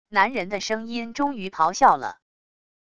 男人的声音终于咆哮了wav音频